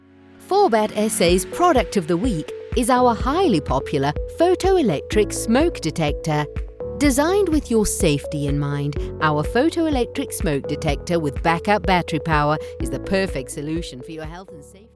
Anglais (sud-africain)
Vidéos d'entreprise
Ma voix est naturelle et chaleureuse, tout en étant résonnante et autoritaire.
Micro Audio Technica AT2020